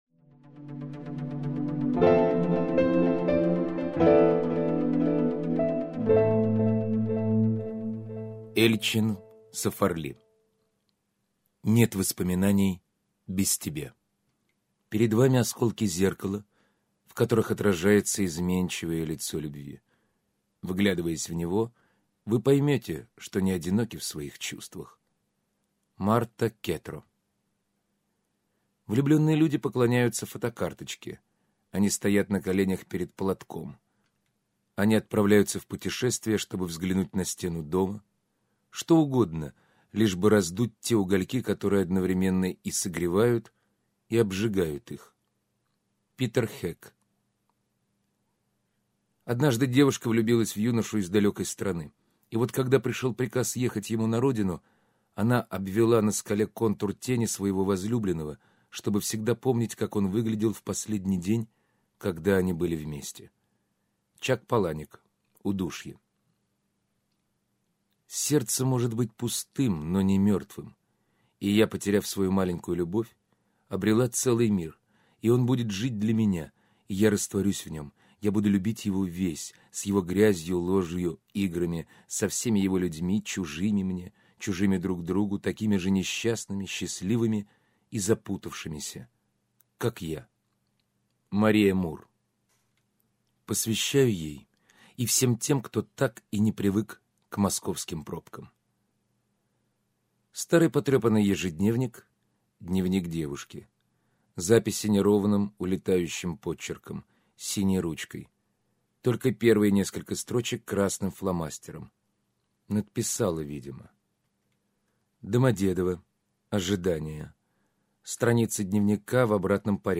Аудиокнига …нет воспоминаний без тебя (сборник) - купить, скачать и слушать онлайн | КнигоПоиск